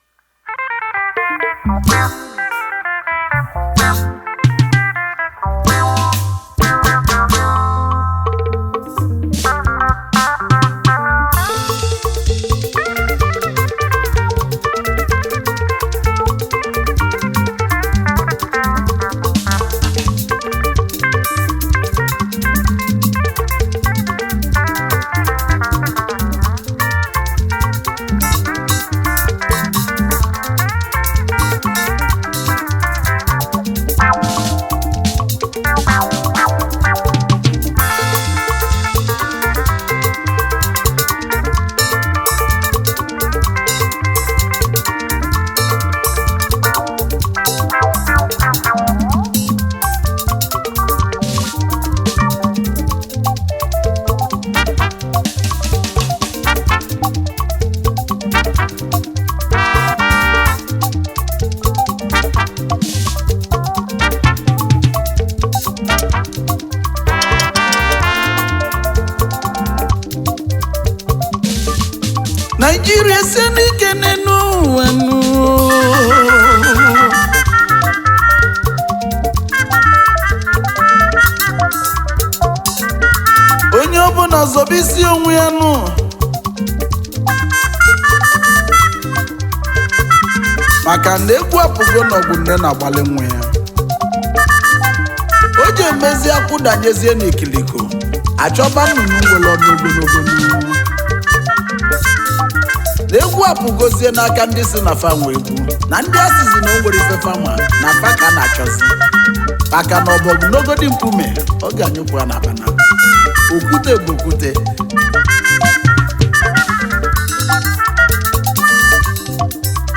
highlife track